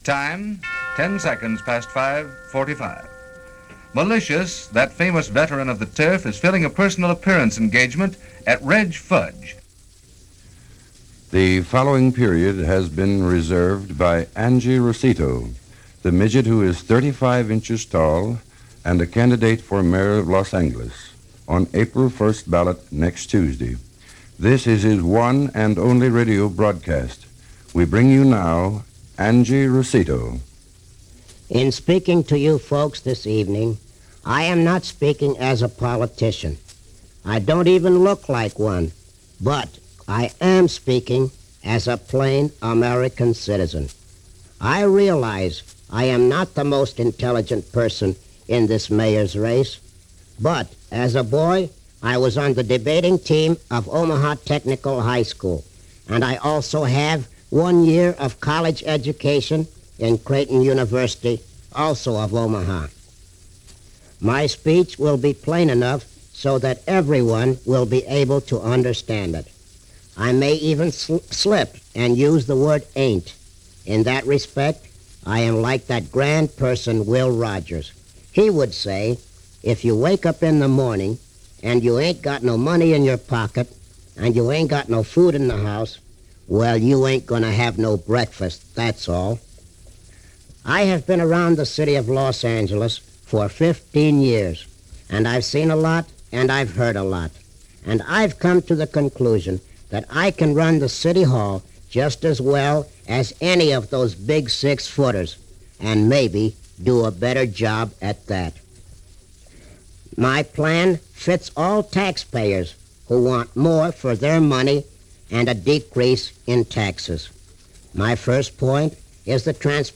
Angelo (or Angi as he’s referred to in this 1941 broadcast) was a well known character actor from the days of Silent films who continued to work all the way up until his death in 1991.
In 1941 he was running for Mayor, most likely as a publicity stunt. The fascinating part of this “campaign address” (which there was only one) are the problems facing a city like Los Angeles which was far from as populated then as it is now – congestion, housing, bureaucracy, and rapid transit.